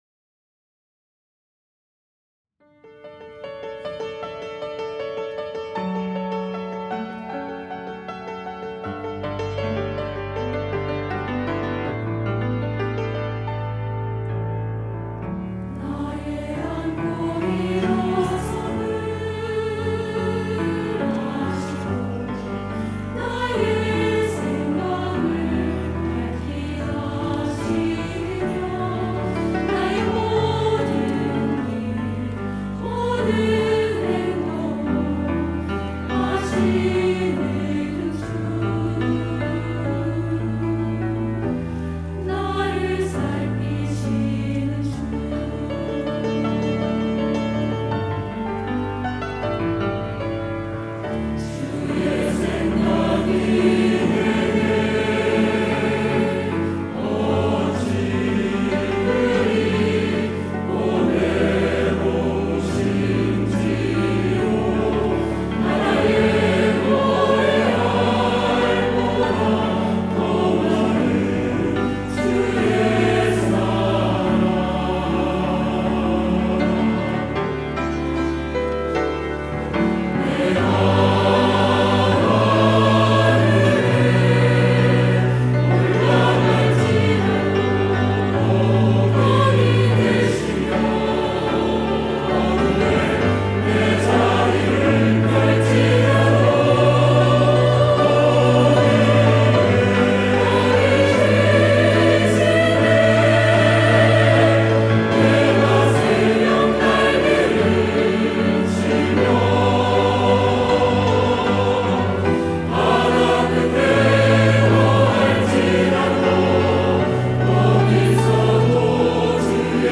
할렐루야(주일2부) - 어디에 거할 지라도
찬양대